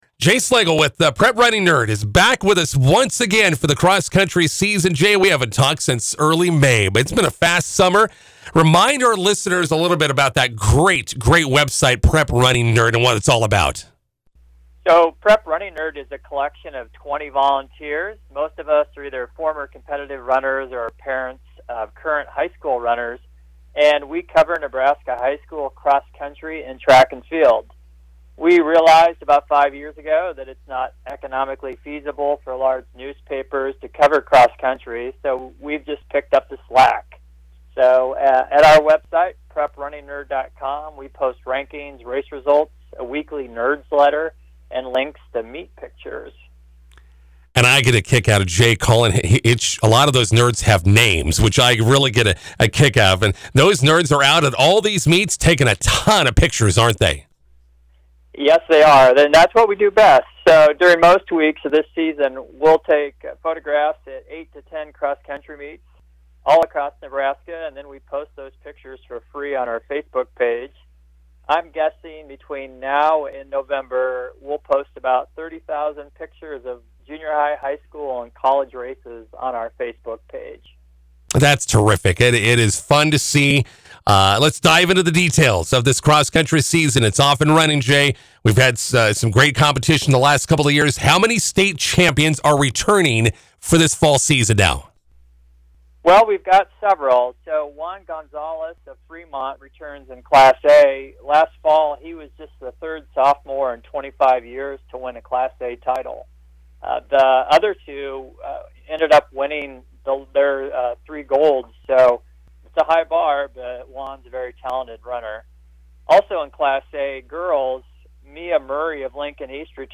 Radio interview
here's the link to our McCook radio interview on August 30.